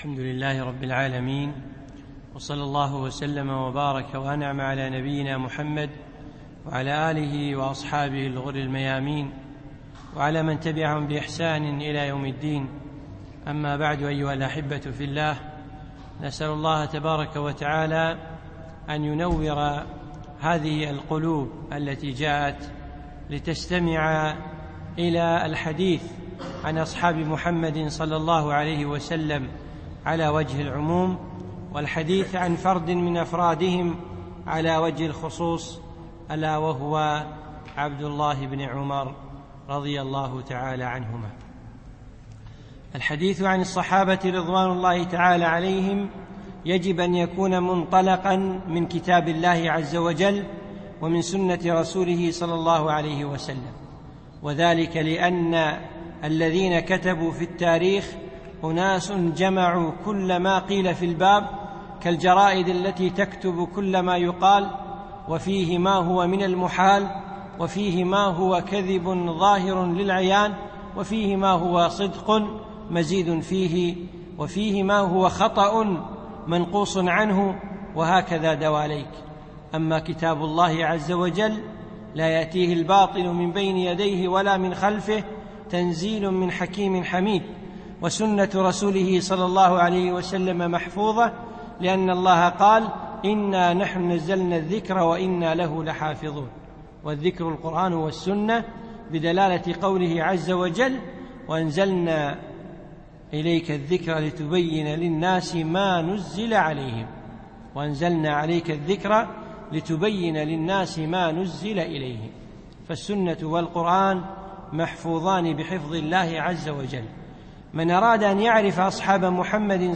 من دروس الشيخ حفظه الله في دولة الإمارات